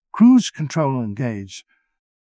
jankboard/client/public/static/voices/en-UK/cruise-control-engaged.wav at ec54f3e36204d2d2c4f2abda6f15218c2f102290
cruise-control-engaged.wav